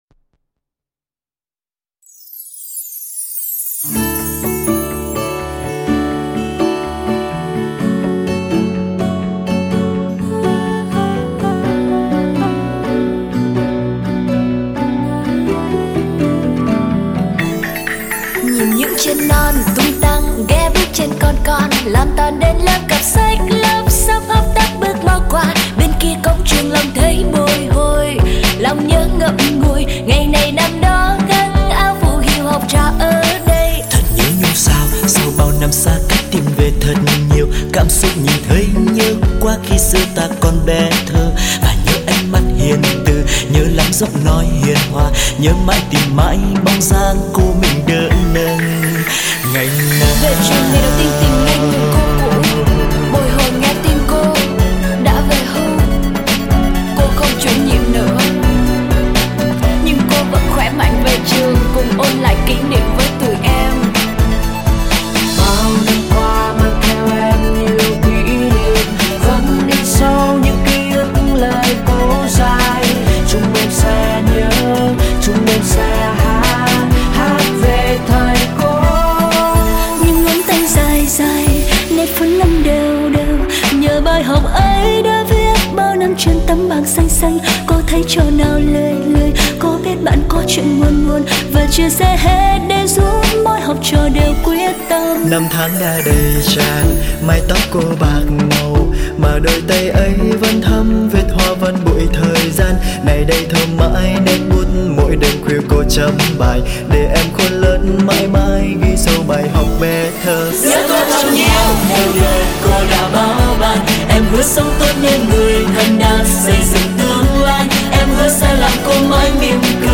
Sách nói | Nhớ cô thật nhiều